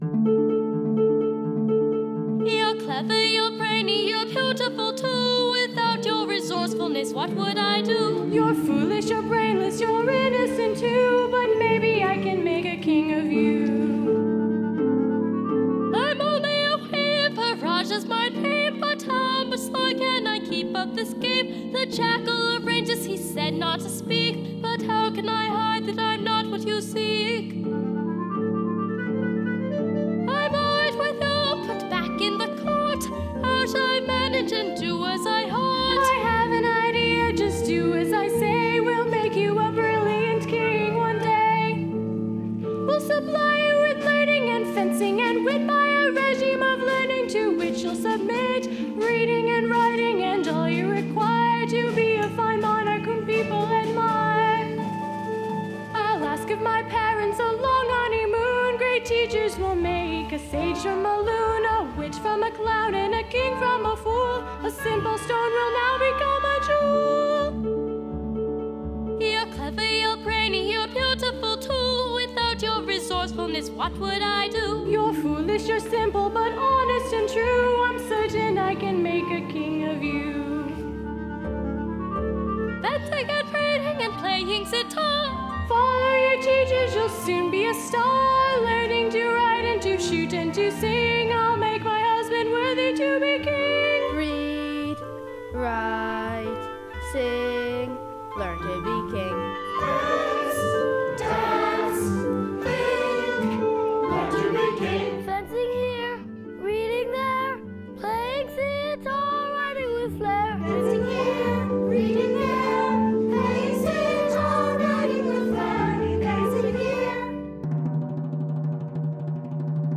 Performed by two intergenerational casts at:
The Weaver's Training sung by Rajah, the Princess, and the chorus